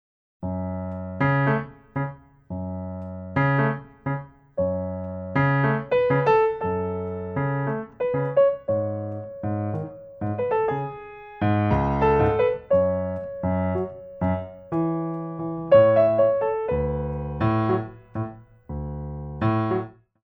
Fondu